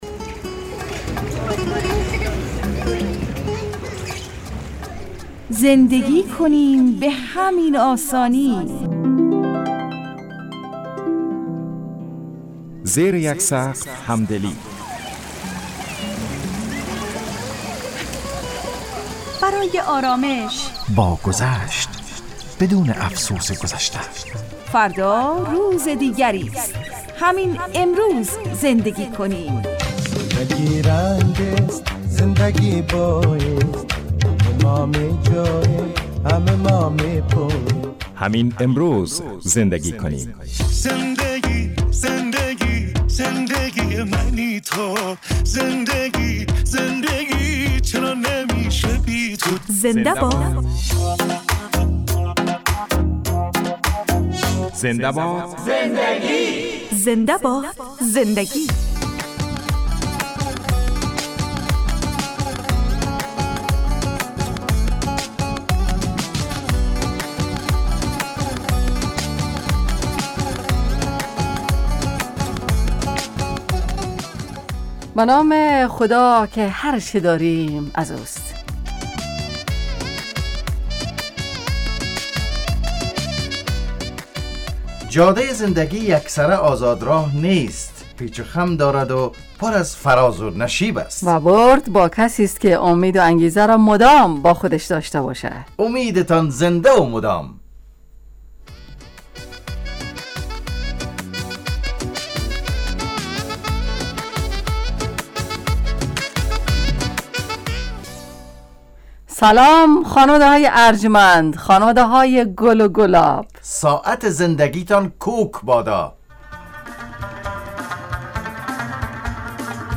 زنده باد زندگی __ برنامه خانوادگی رادیو دری__ زمان پخش ساعت